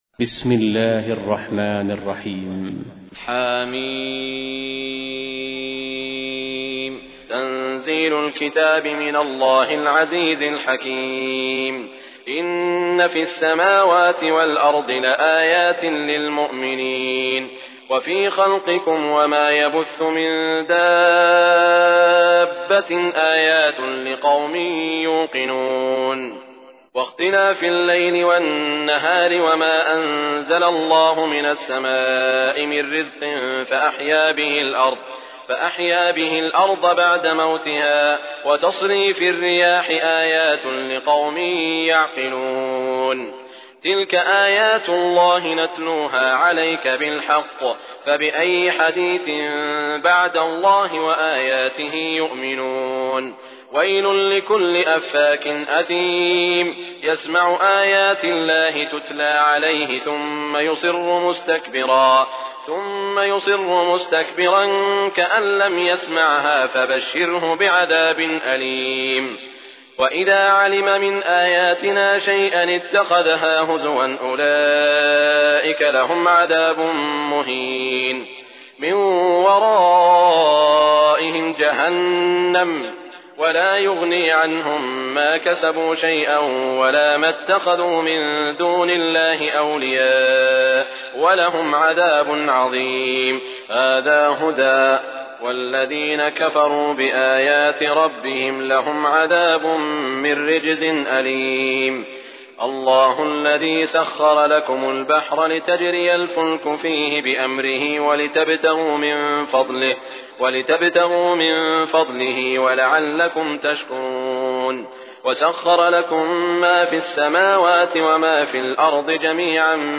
مصاحف - سعود بن إبراهيم الشريم
المصحف المرتل - حفص عن عاصم